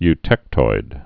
(y-tĕktoid)